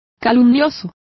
Also find out how calumniosas is pronounced correctly.